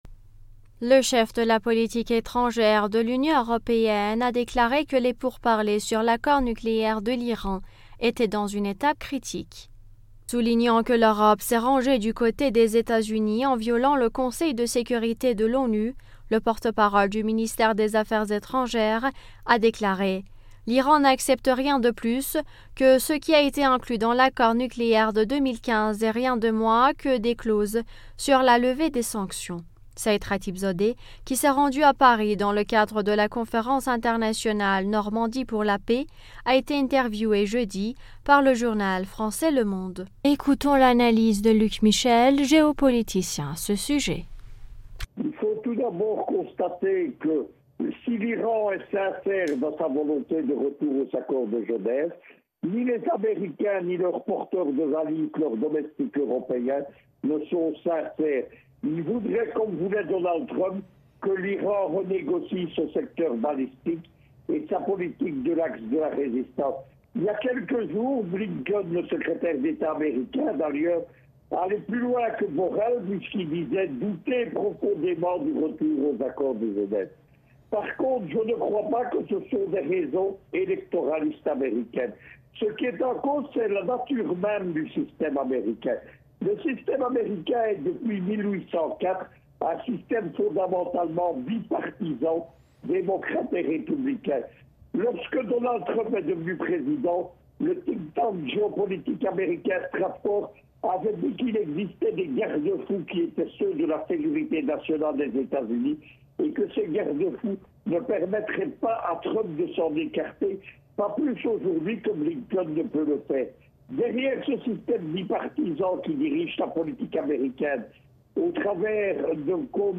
géopoliticien, s’exprime sur le sujet.